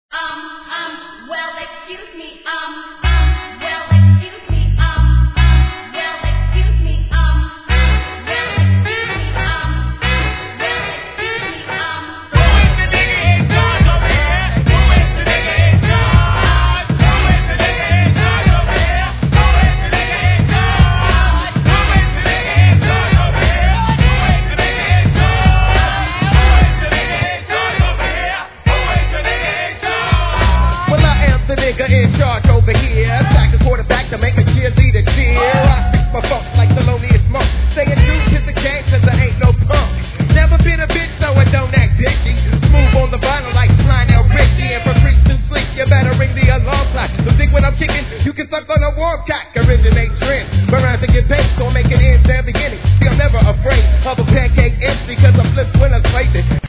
HIP HOP/R&B
シングル未カットの数々も全体を通してCOOLかつキャッチー!!